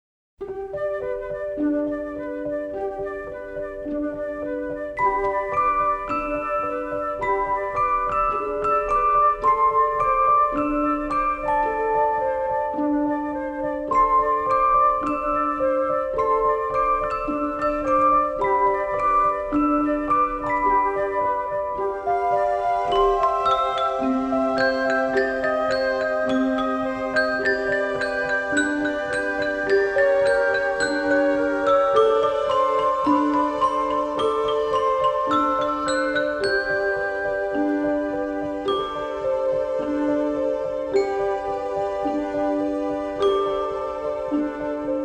jazz and mood cues
shimmering with color, charm and melody